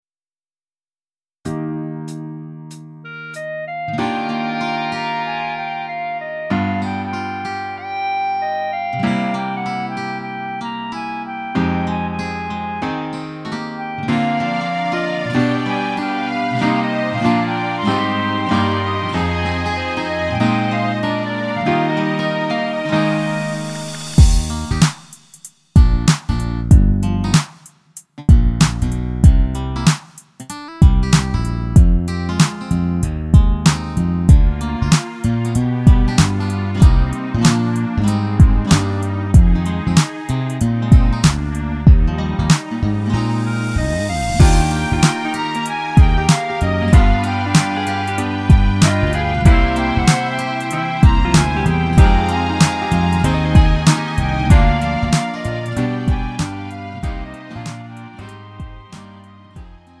음정 -1키 3:14
장르 가요 구분 Lite MR